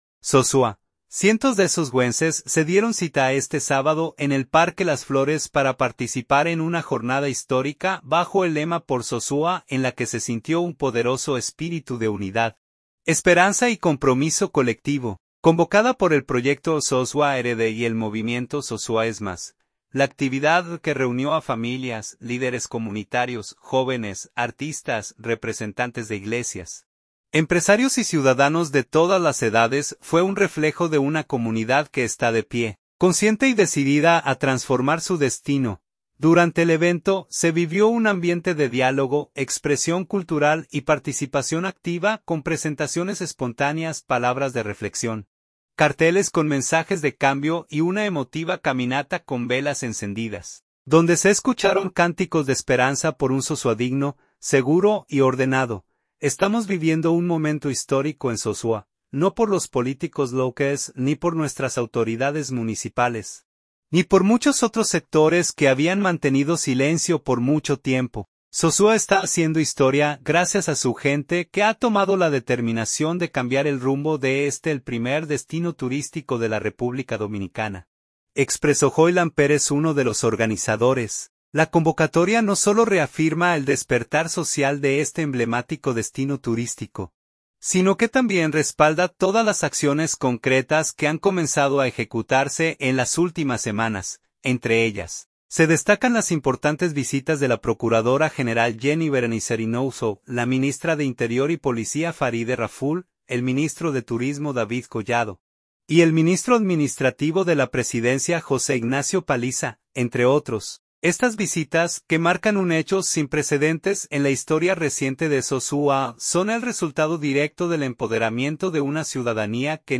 Histórica convocatoria reúne a cientos de ciudadanos en el Parque Las Flores y la calle Pedro Clisante de Sosúa. | El Nuevo Norte
Durante el evento, se vivió un ambiente de diálogo, expresión cultural y participación activa, con presentaciones espontáneas, palabras de reflexión, carteles con mensajes de cambio, y una emotiva caminata con velas encendidas, donde se escucharon cánticos de esperanza por un Sosúa digno, seguro y ordenado.